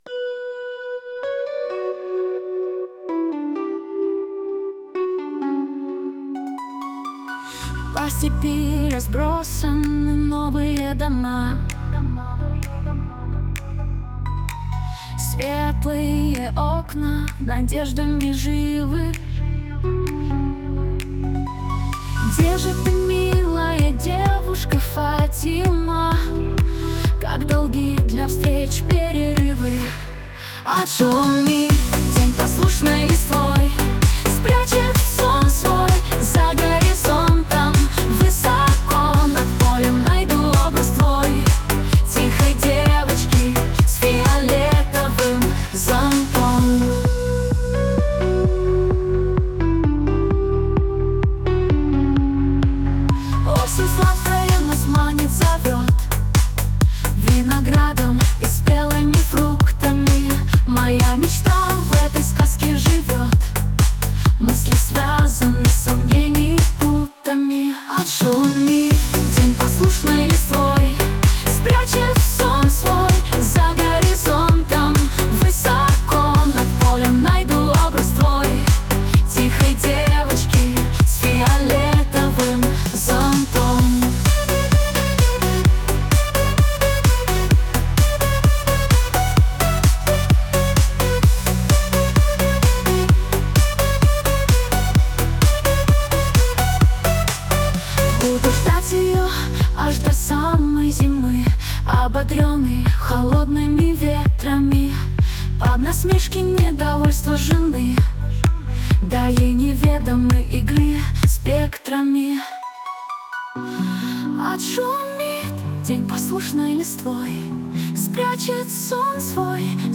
ТИП: Пісня
СТИЛЬОВІ ЖАНРИ: Ліричний
12 Чудова пісня! friends hi